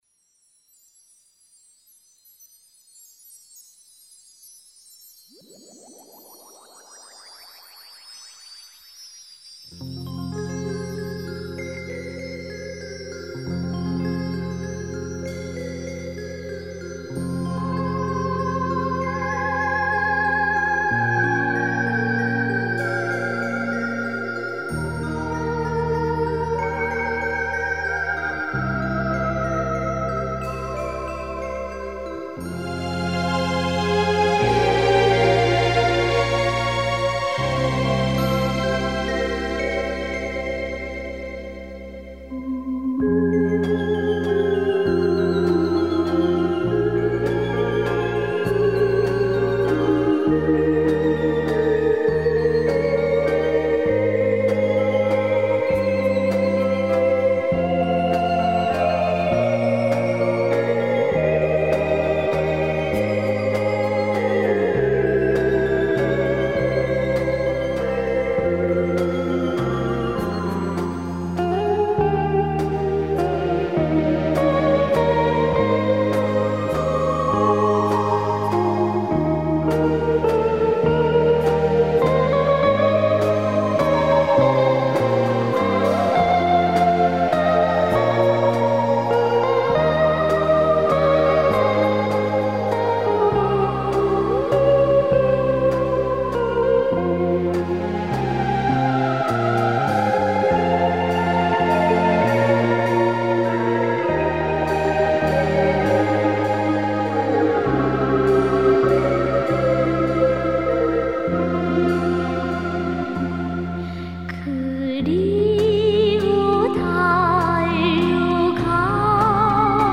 [17/5/2009]一首沁人心脾的北朝鲜女声独唱歌曲 激动社区，陪你一起慢慢变老！